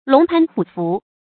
龍蟠虎伏 注音： ㄌㄨㄙˊ ㄆㄢˊ ㄏㄨˇ ㄈㄨˊ 讀音讀法： 意思解釋： 雄踞貌。